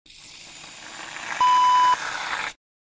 Shaving cream sound effect .wav
Description: Dispensing shaving cream into hand
Properties: 48.000 kHz 16-bit Stereo
A beep sound is embedded in the audio preview file but it is not present in the high resolution downloadable wav file.